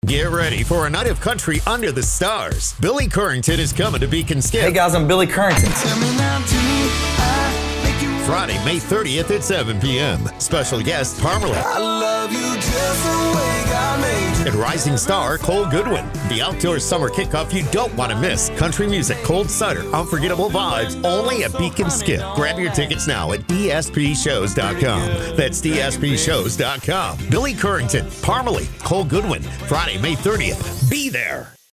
Middle Aged
Senior